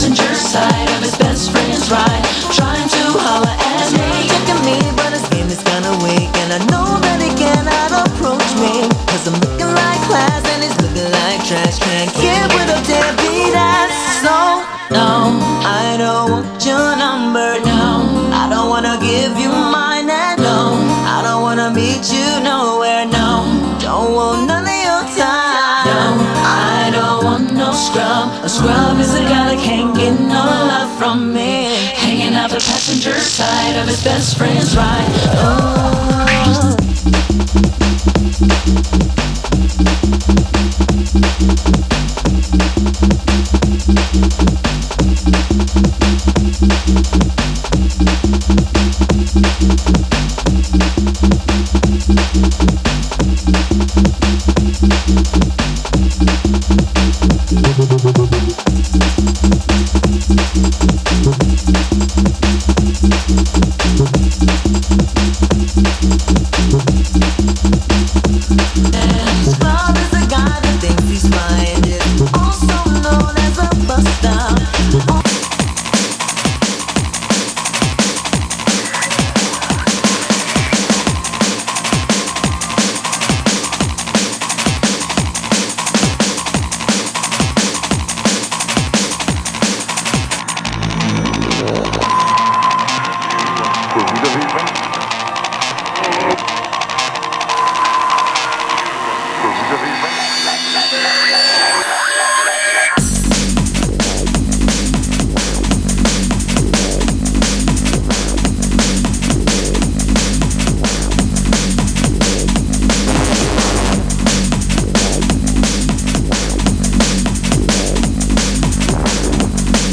Drum N Bass